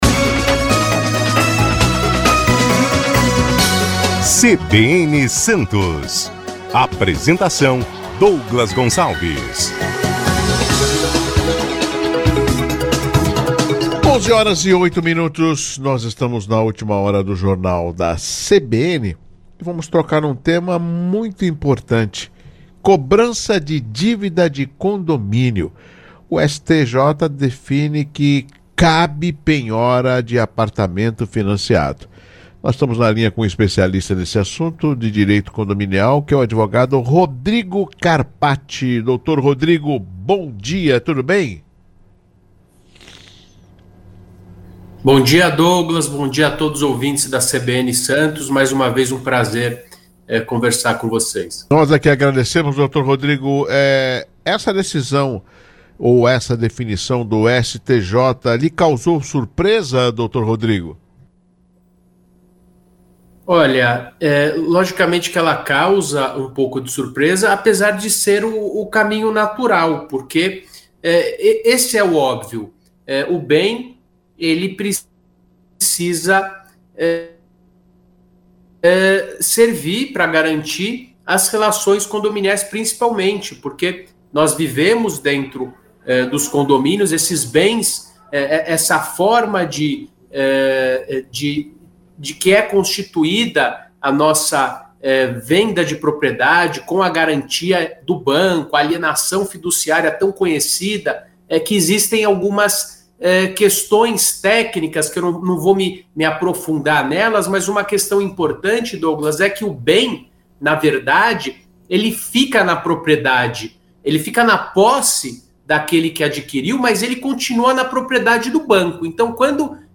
Em entrevista à Rádio CBN Santos